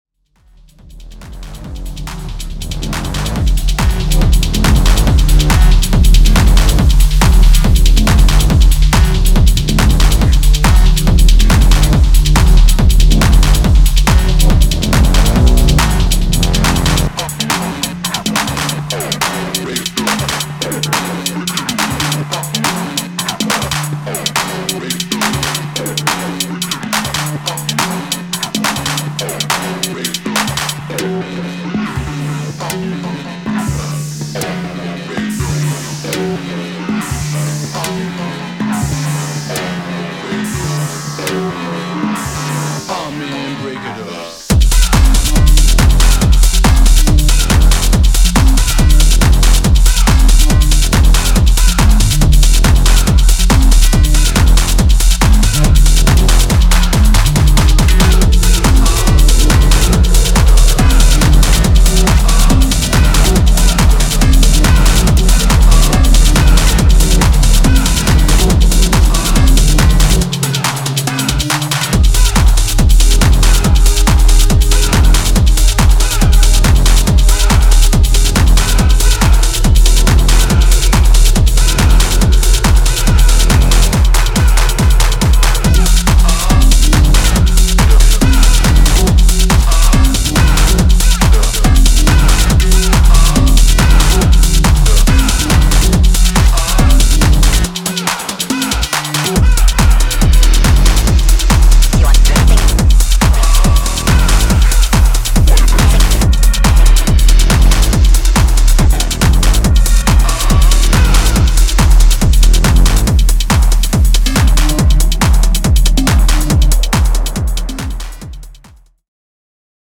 Relentless techno bangers with an acidic touch.